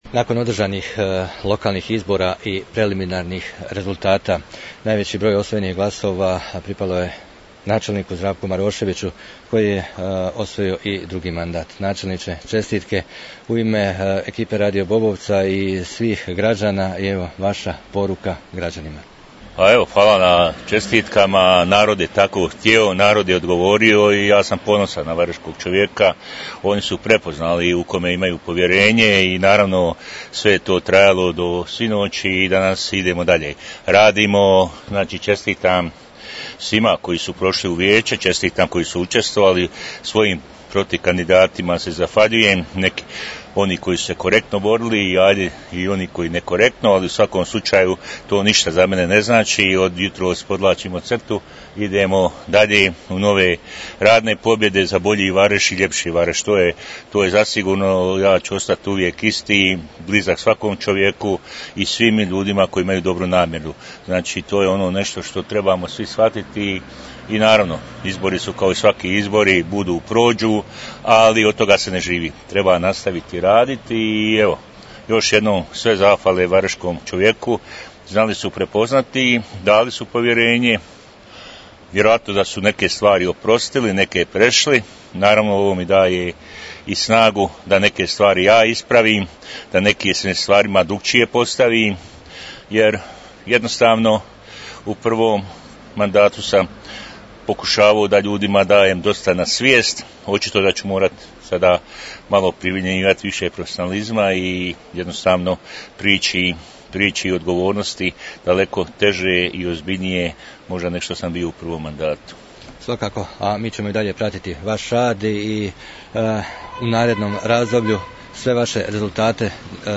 Nakon što su objavljeni preliminarni i neslužbeni rezultati načelnik Zdravko Marošević uputio je poruku i zahvalu građanima ....